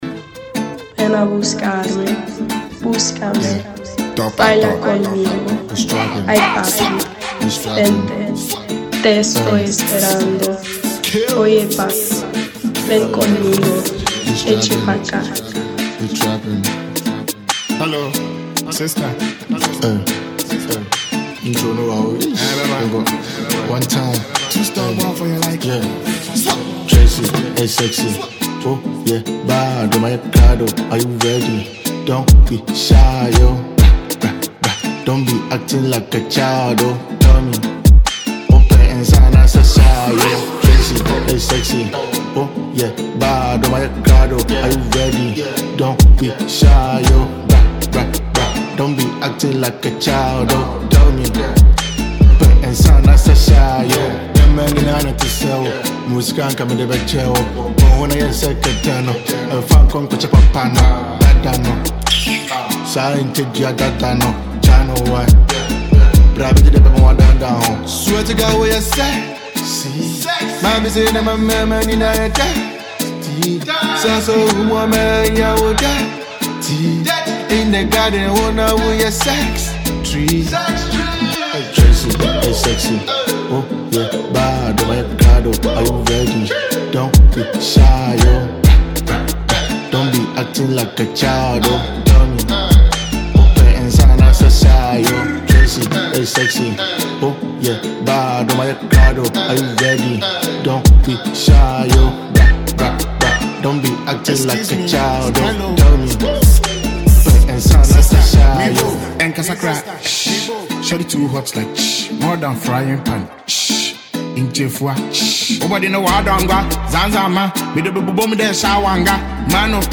a Ghanaian asakaa rapper